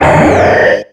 Cri d'Avaltout dans Pokémon X et Y.